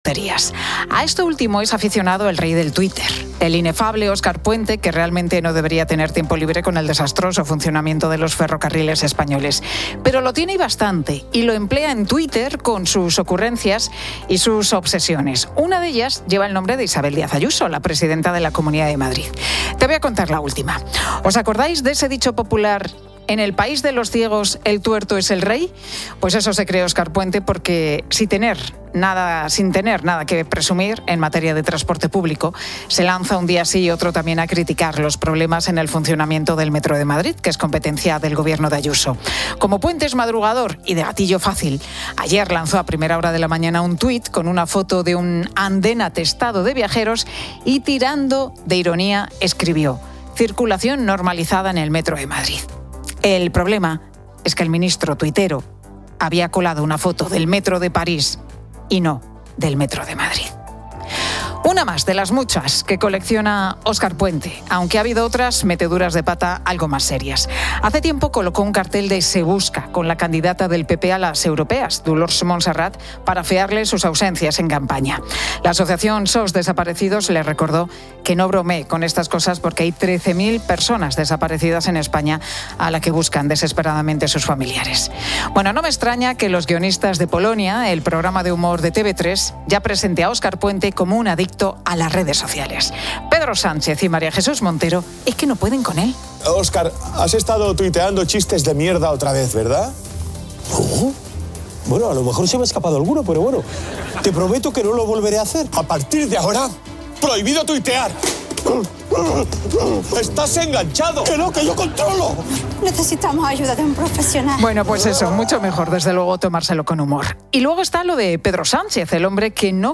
La Tarde 16:00H | 15 OCT 2025 | La Tarde Pilar García Muñiz cuenta que Ábalos se ha negado a declarar en el Supremo mientras las acusaciones populares piden su ingreso en prisión, aunque la Fiscalía no lo respalda.